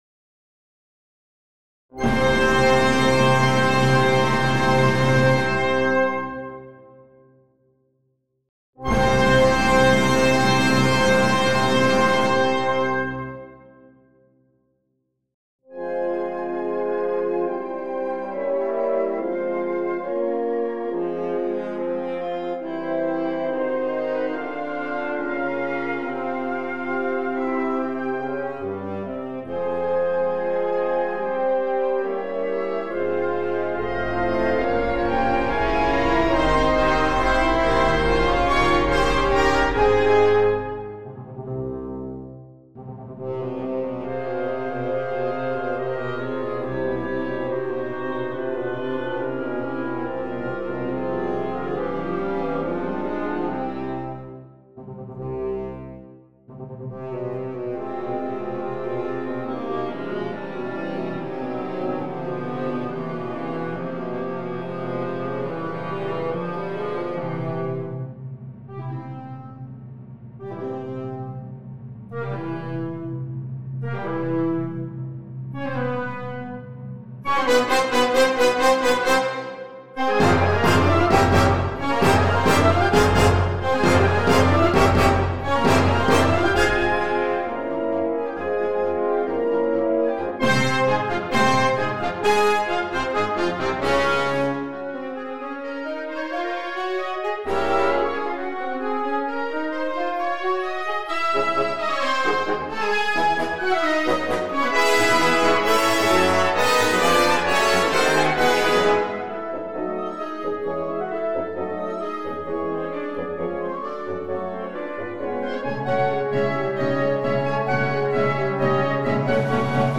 A tremolo/drone then supports a descending melodic line performed by the clarinets. Rapid descending arpeggios in an anapestic rhythm anticipate the opening gesture of the main theme of the Allegro Vivo.
The Allegro Vivo is organized into two large sections (A – A1), each containing several episodes characterized by different thematic ideas, often unified by the opening gesture of the first theme.
Sinfonia Op. 106 - Audio version symphonic